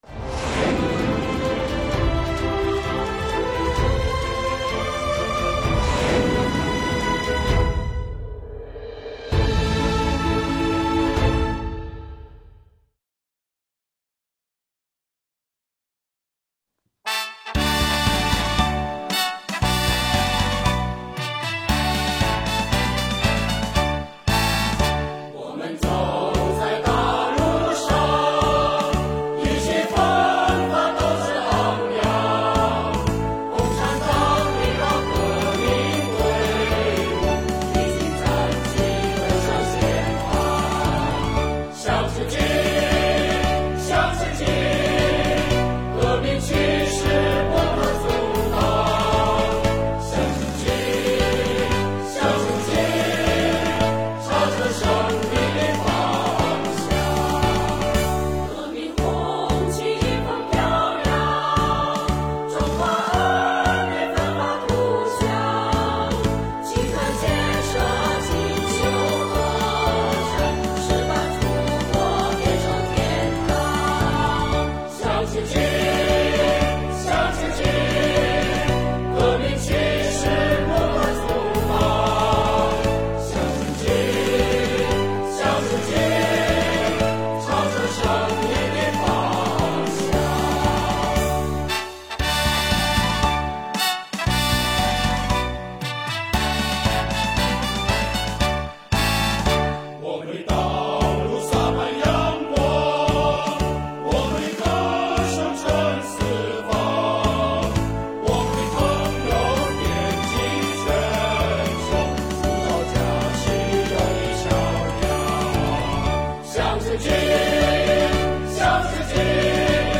他们的歌声慷慨激昂，他们的精神奋进昂扬。